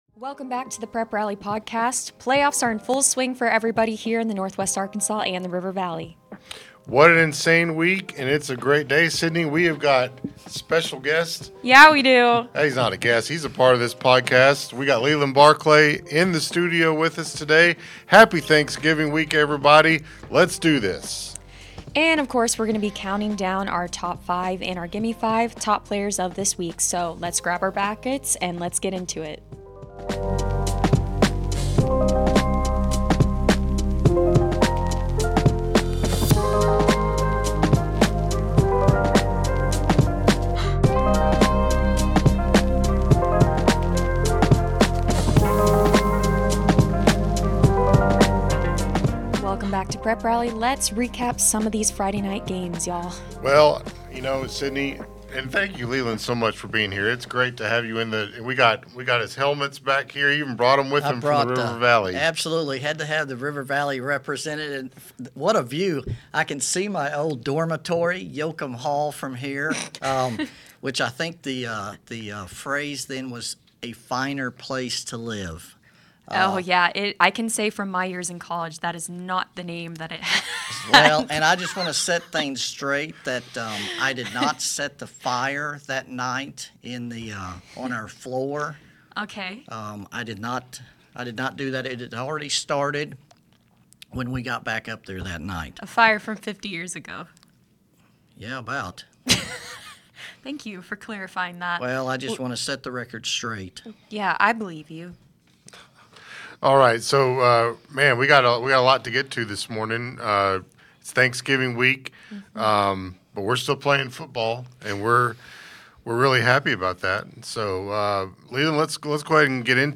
are joined by a special guest in the studio